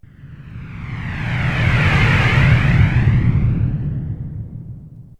Jetflyby
JetFlyBy.wav